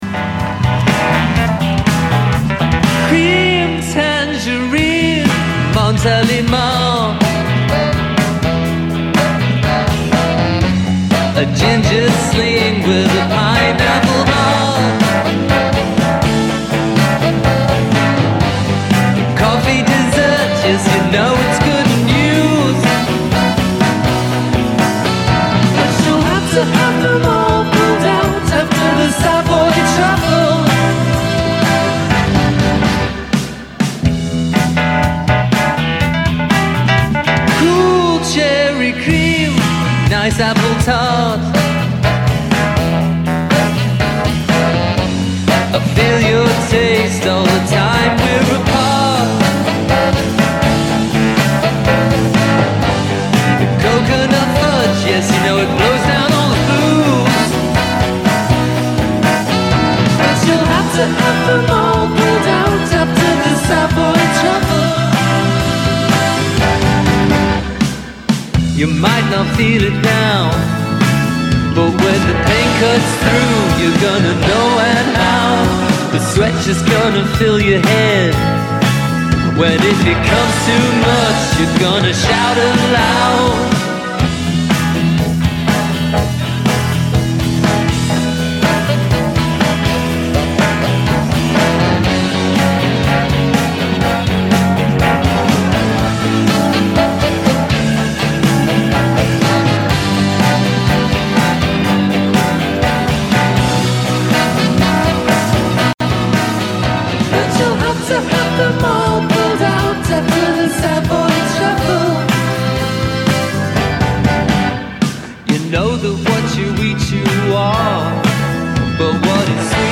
Con esta excusa fue que Un Mundo Cualquiera recibió al humorista y escritor para conversar de todo un poco.